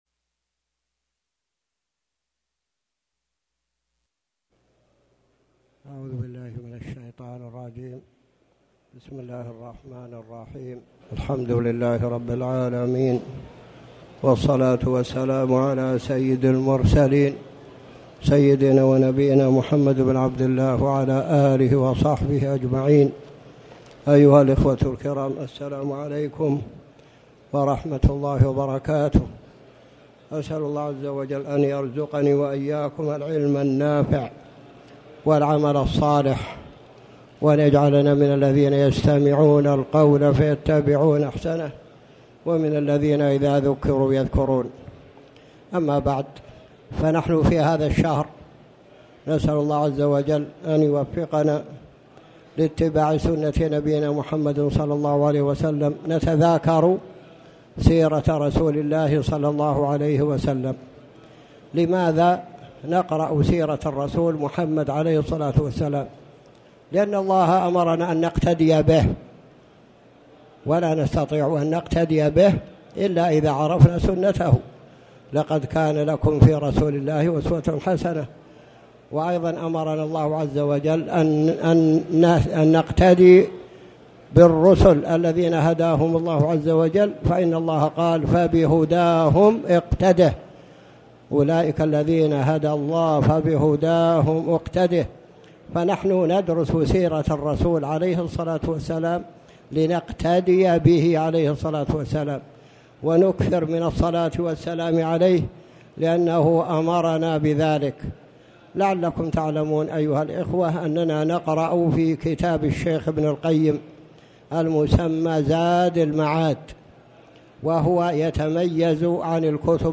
تاريخ النشر ٤ رجب ١٤٣٩ هـ المكان: المسجد الحرام الشيخ